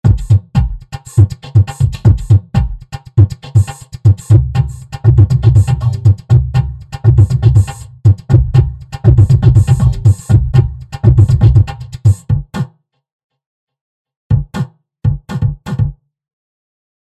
Audioease Altiverb, im geschlossenen Blecheimer (Originalsignal weit heruntergefahren):